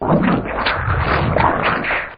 c_gettin_hit1.wav